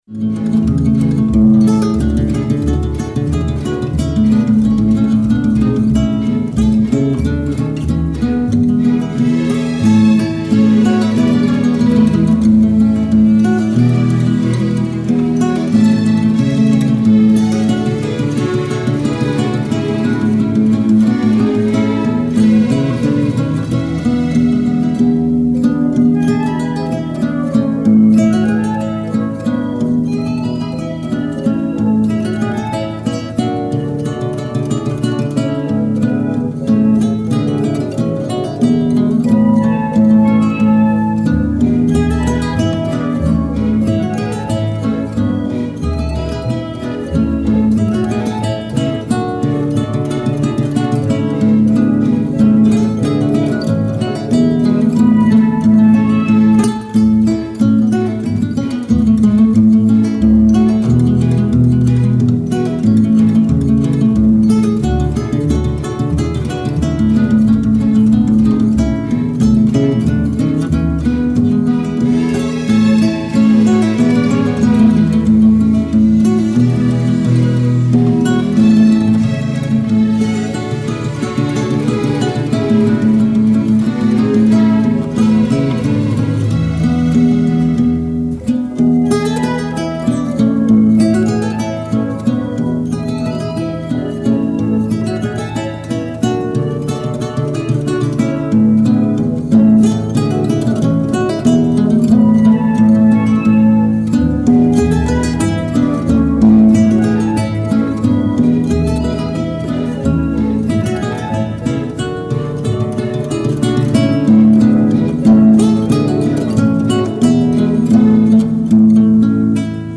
0315-吉他名曲帕格尼的小步舞曲.mp3